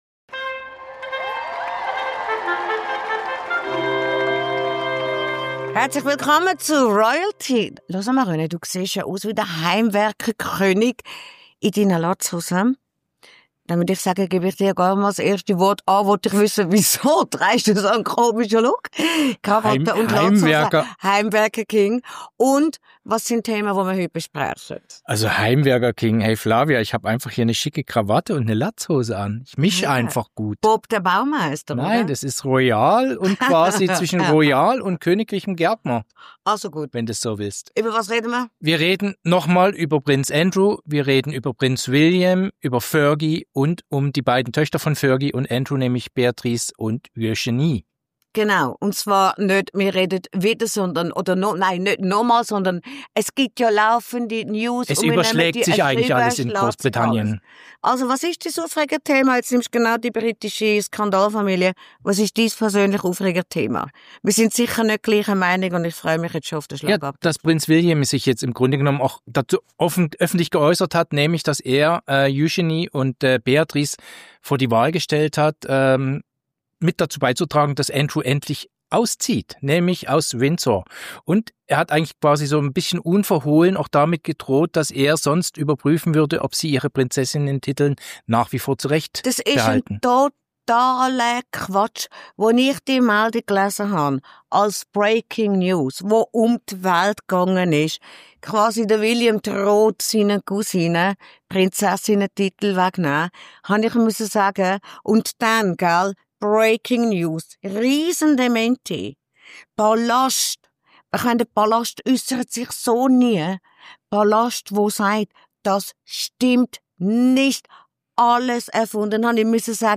Beschreibung vor 5 Monaten Die Meldung ging als Breaking News um die Welt: Der britische Thronfolger soll seinen Cousinen Eugenie und Beatrice mit royalem Titelentzug gedroht haben. Unser «RoyalTea»-Expertenduo zofft sich über diesen unglaublichen Vorwurf. Zudem: Prinz Harrys Sauermiene zu Halloween und das umstrittene Koks-Buch über Mette-Marits Sohn landet vor Gericht.